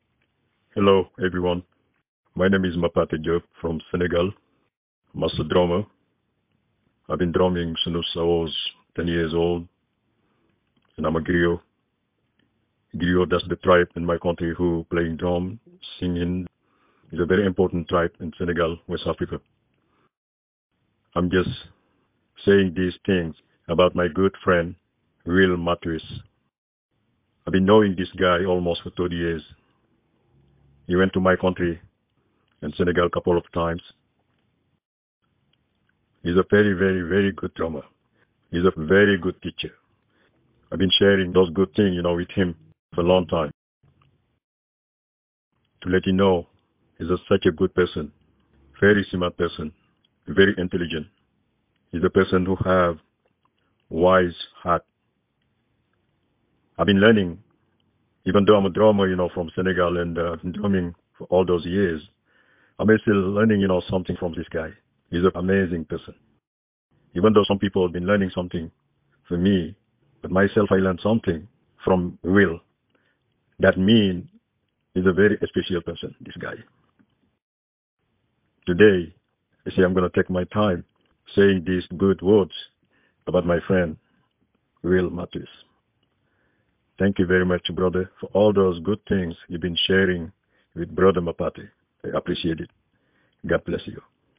Master Drummer